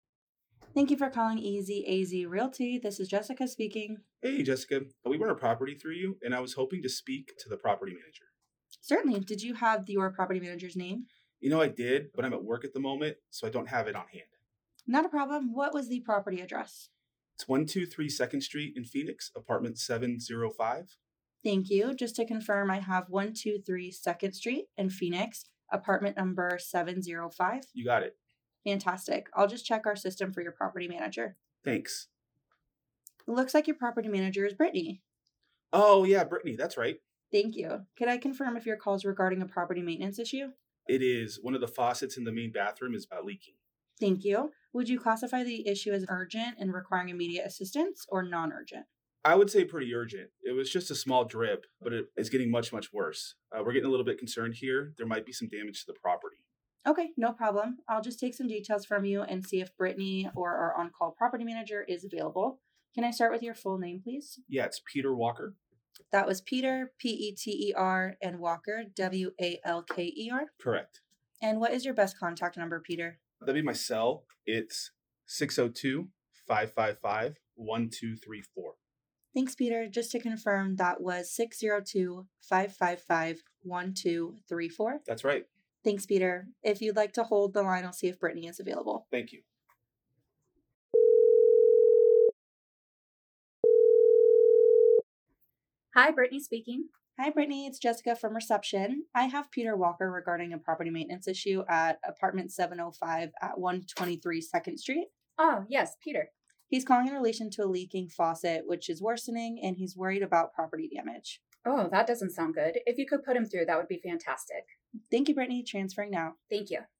phone-answering-virtual-assistant-sample-call-MyAssistant-RHQ.mp3